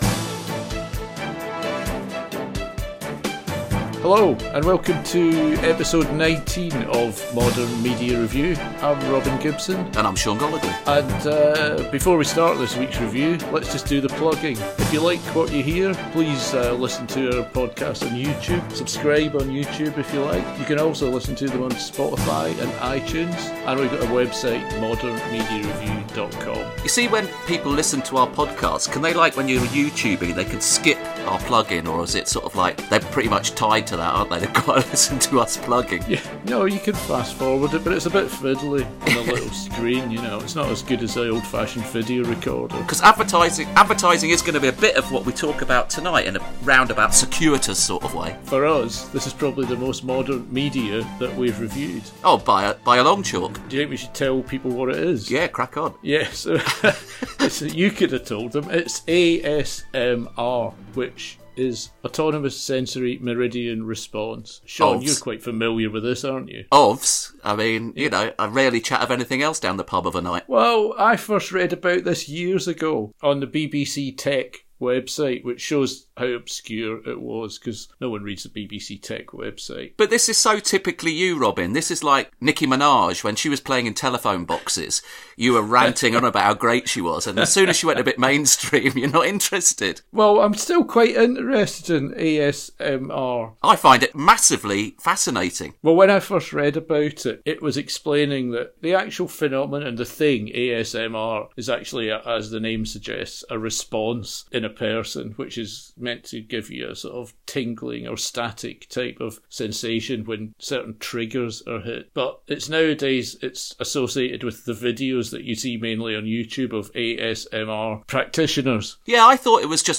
Contains triggers.